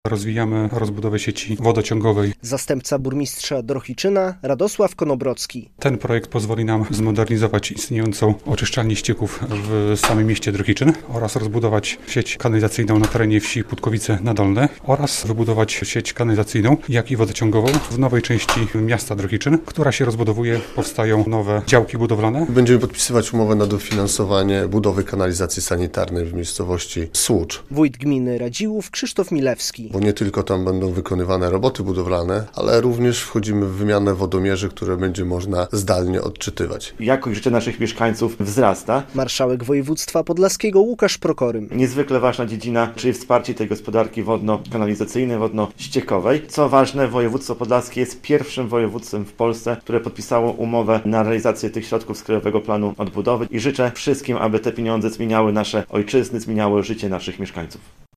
39 mln zł z Krajowego Planu Odbudowy na różne inwestycje wodno-kanalizacyjne dostanie 10 podlaskich samorządów - poinformowano w piątek (7.03) na konferencji prasowej w urzędzie marszałkowskim w Białymstoku.
39 mln zł z KPO dla 10 gmin na inwestycje wodno-kanalizacyjne - relacja